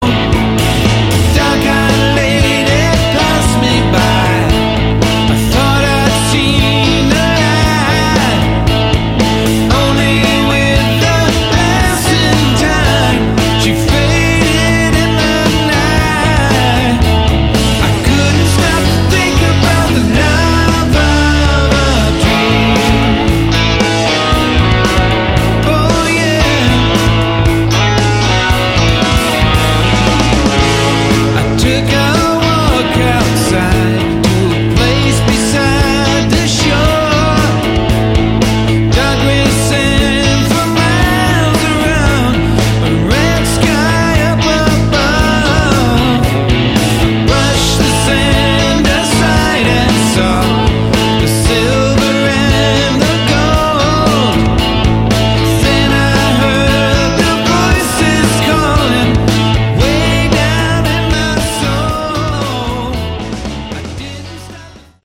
Category: Melodic Rock
lead vocals, backing vocals
guitar
keyboards
bass
drums